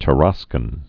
(tə-räskən) also Ta·ras·co (-räskō)